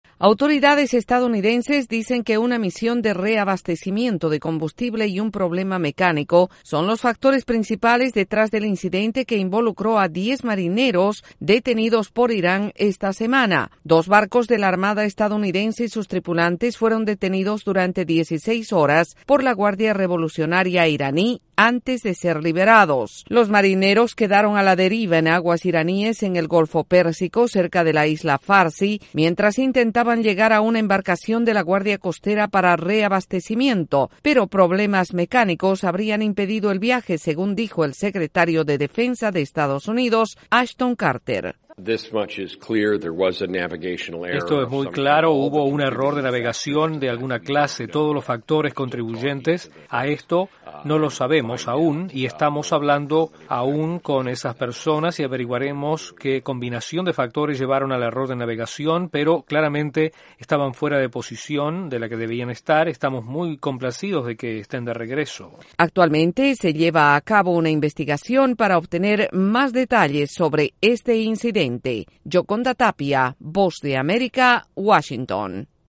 El Departamento de Defensa continúa la investigación sobre el incidente en Irán en el que 12 marinos estadounidenses fueron detenidos. Desde la Voz de América en Washington informa